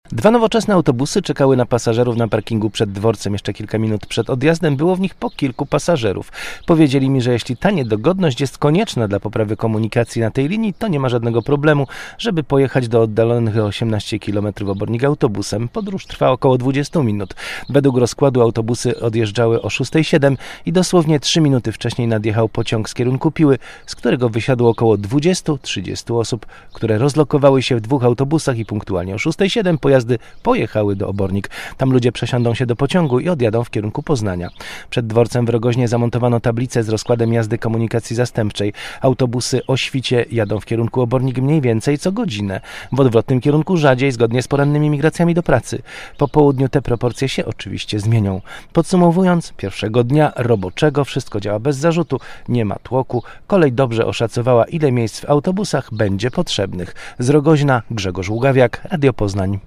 Przed dworcem w Rogoźnie zamontowano tablice z rozkładem jazdy komunikacji zastępczej. Był tam nasz reporter.